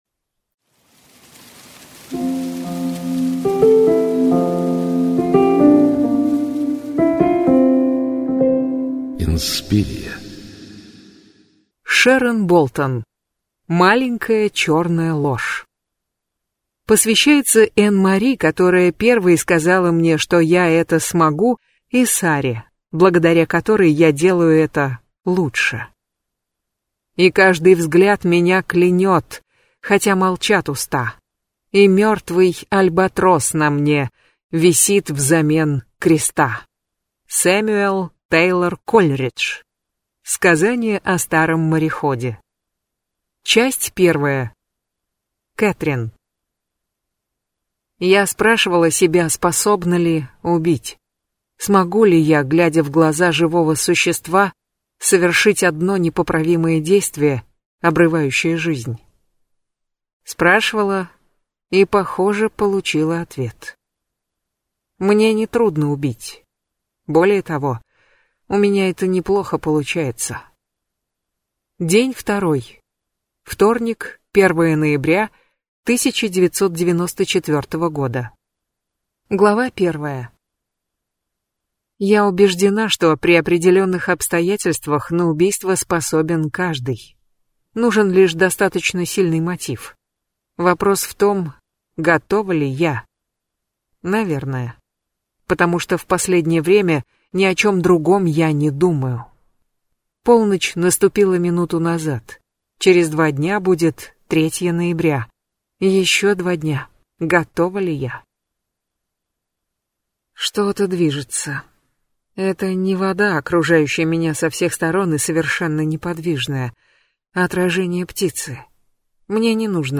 Аудиокнига Маленькая черная ложь | Библиотека аудиокниг